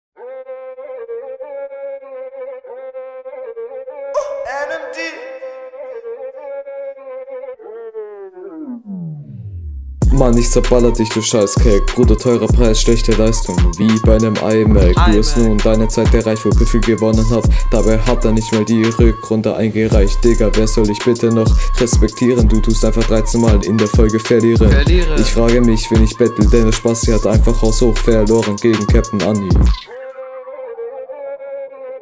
Flow: ➨ Fühle deinen Flow nicht. ➨ Flowpattern nicht besonders gut umgesetzt. ➨ Zeilenlängen unangenehm …